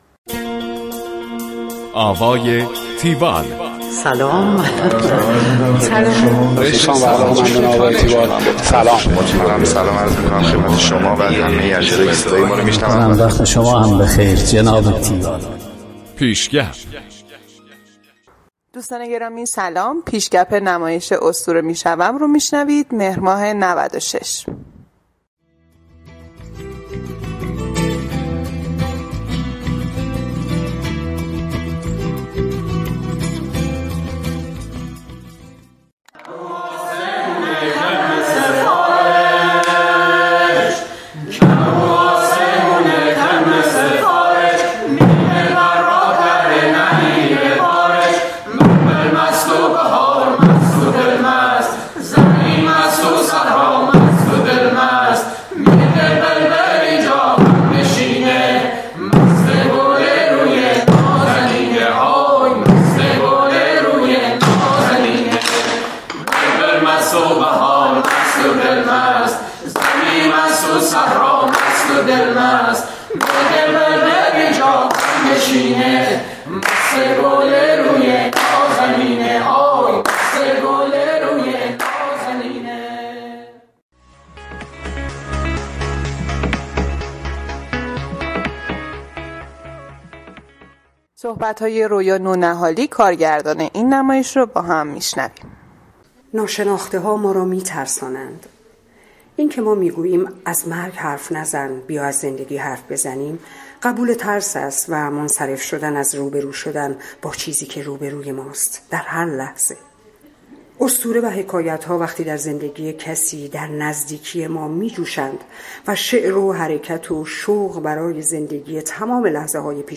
گزارش آوای تیوال از نمایش اسطوره می شوم